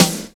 64 SNARE 2.wav